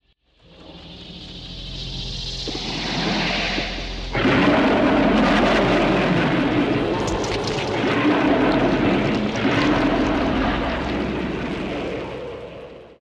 Adds new changeling related sounds.